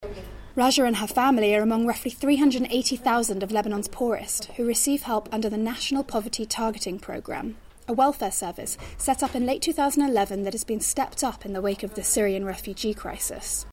【英音模仿秀】欠发达国家中的难民 听力文件下载—在线英语听力室